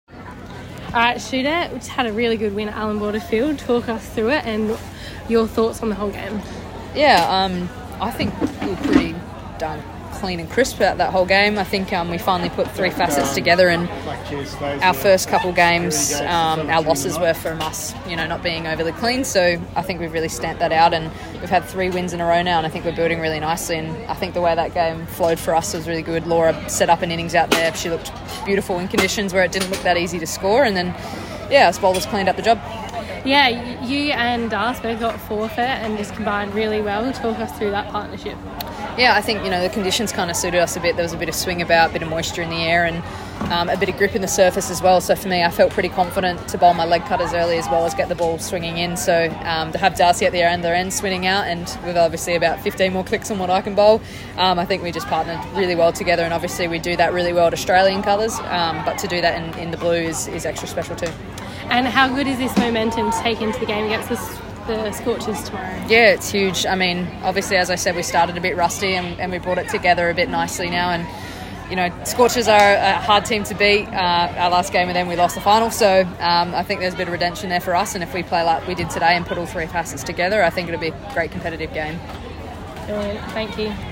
Megan Schutt speaks following Strikers win over Brisbane Heat